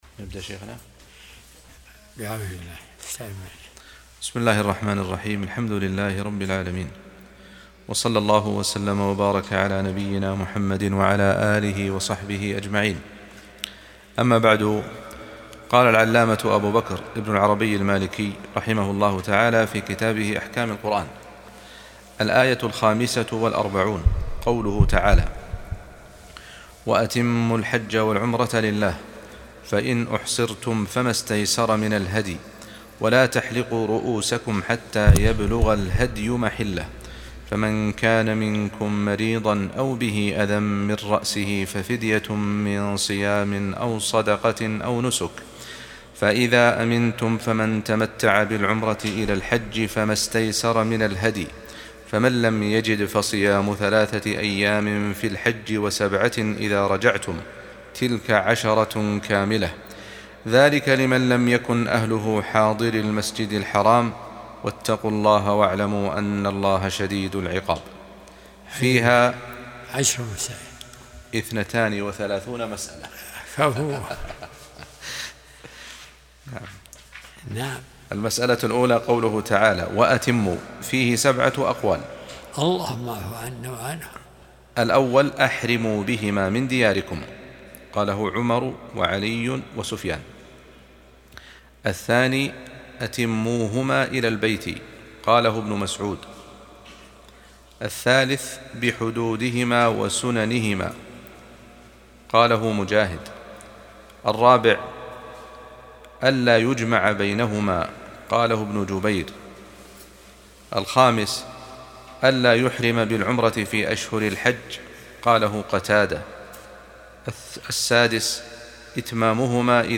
درس الأحد 65